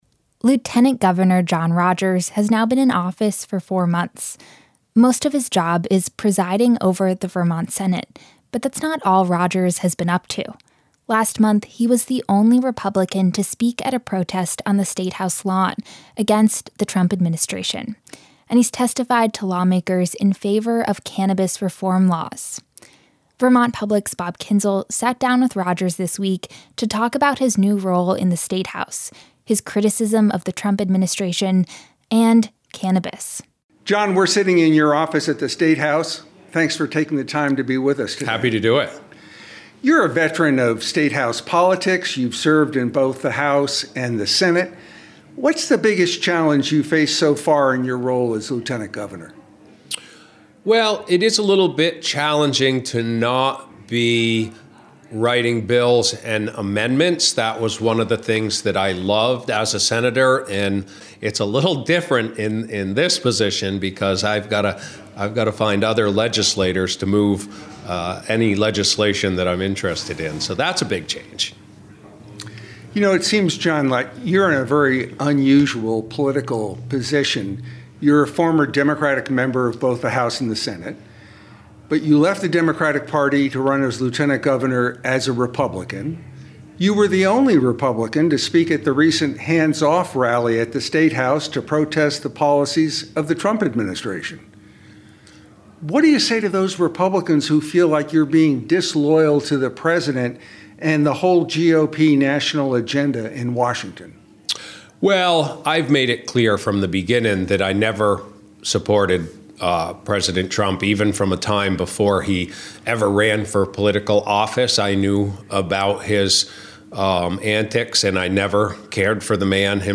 Local news, reporting and newscasts from Vermont Public.